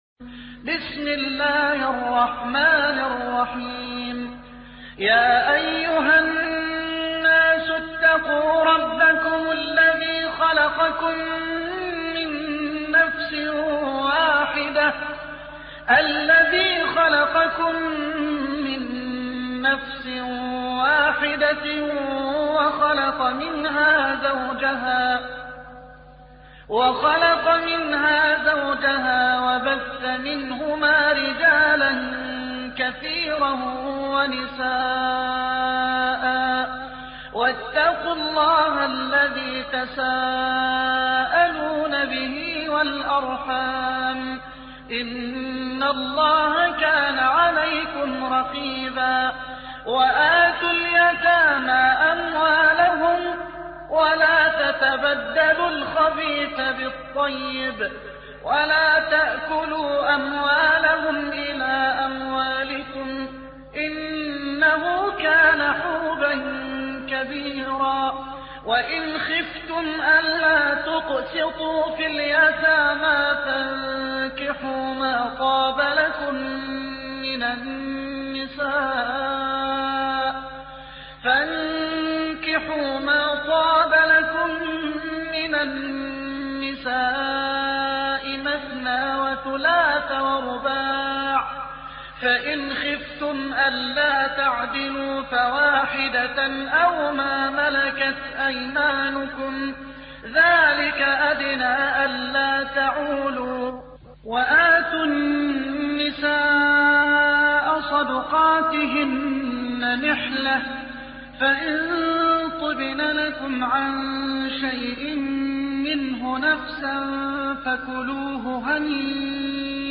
4. سورة النساء / القارئ